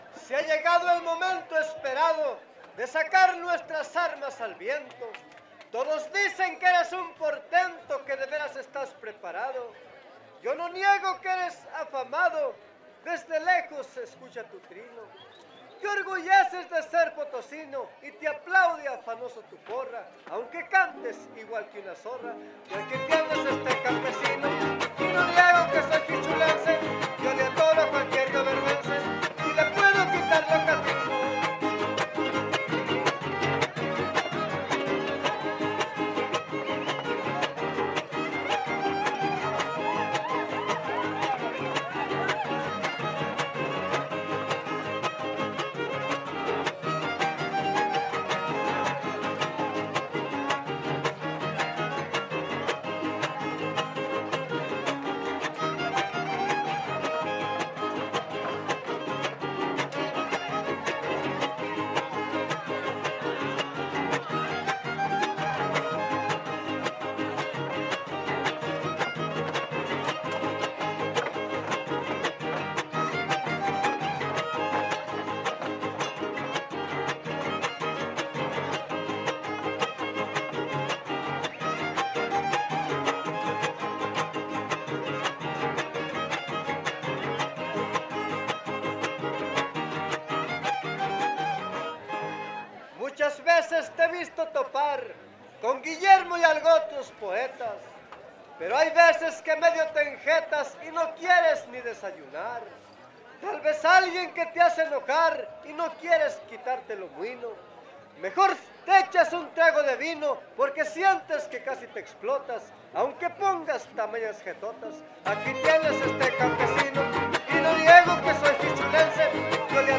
Décima
Huapango arribeño
violín primero
guitarra huapanguera
Violín Guitarra Vihuela
Topada ejidal: Cárdenas, San Luis Potosí